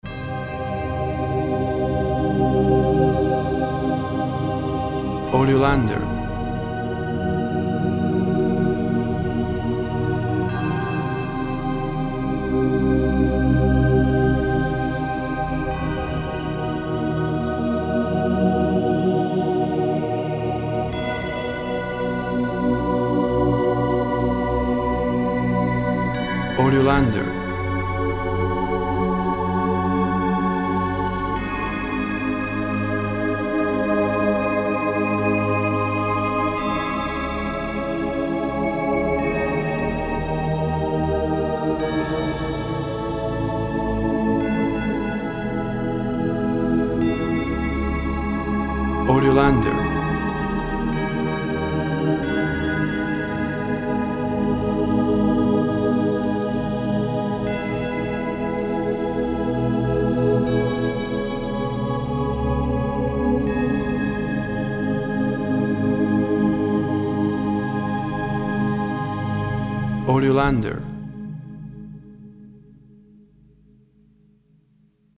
Eerie and haunting organ and chimes.
Tempo (BPM) 60